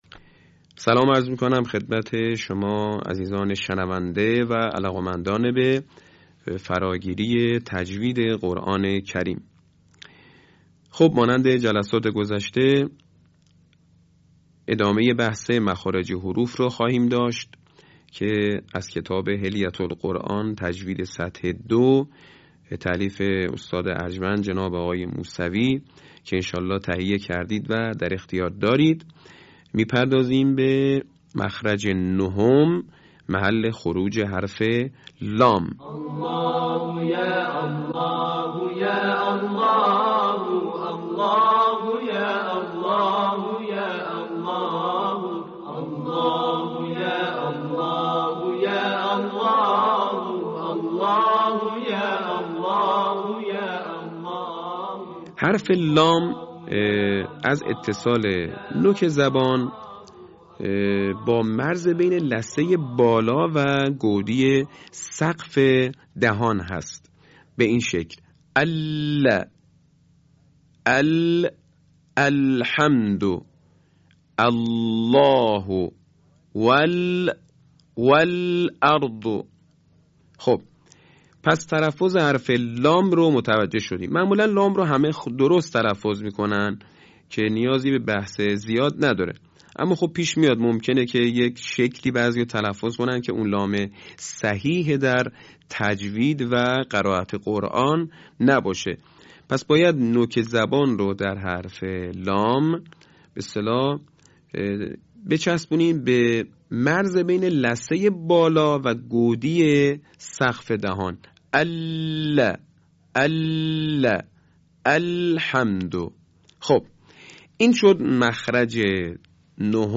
صوت | آموزش تجوید موضع لسان